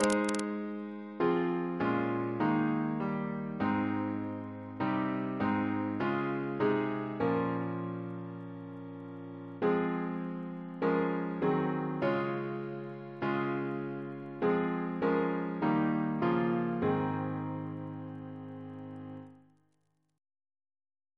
Double chant in A♭ Composer: George Thalben-Ball (1896-1987), Organist of the Temple Church Reference psalters: AP: 92-1; RSCM: 133